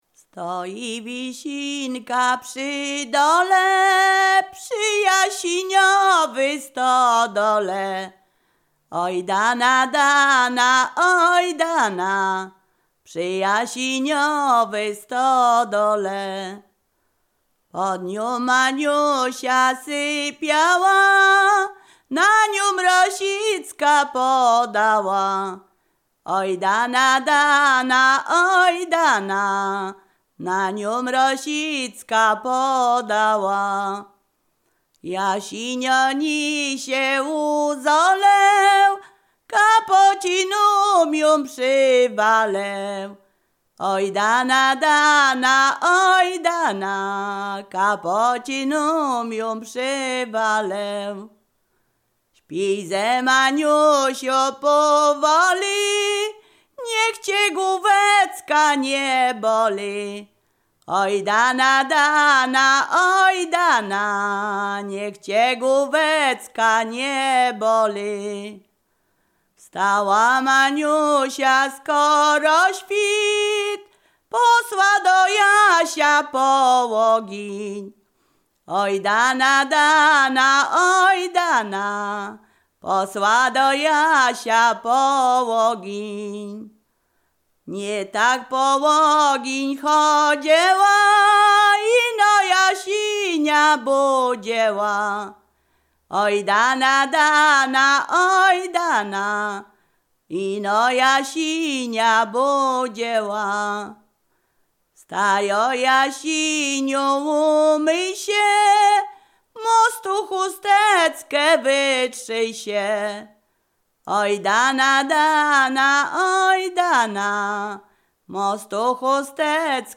Ziemia Radomska
liryczne miłosne